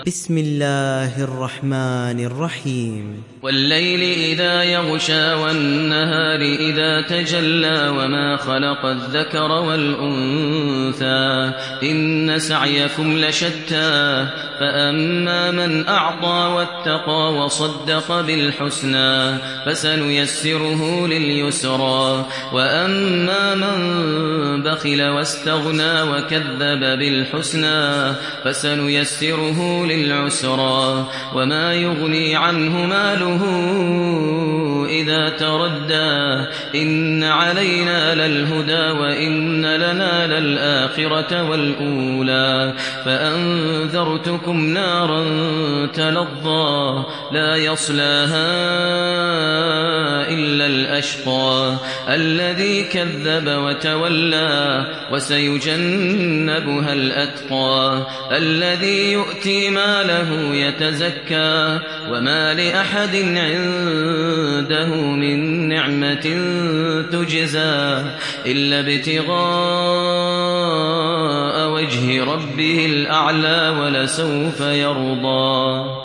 Leyl Suresi İndir mp3 Maher Al Muaiqly Riwayat Hafs an Asim, Kurani indirin ve mp3 tam doğrudan bağlantılar dinle